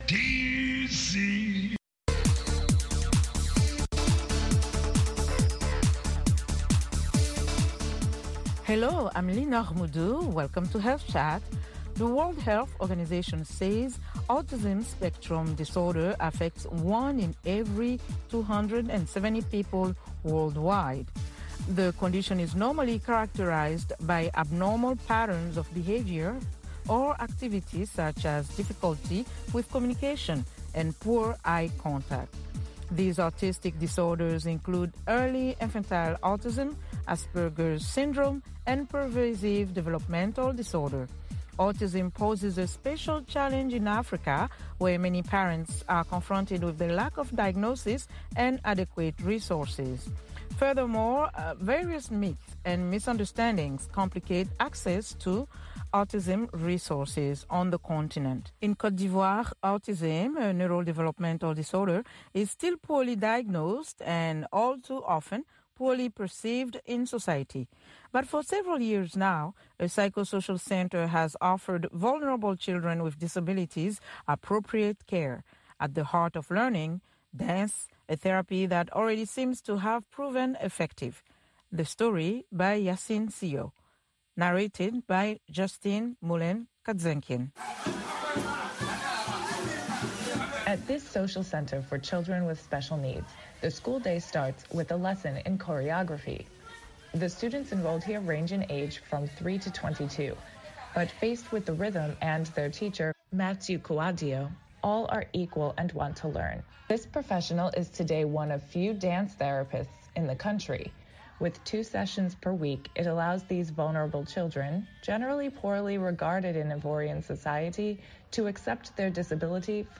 Health Chat is a live call-in program that addresses health issues of interest to Africa.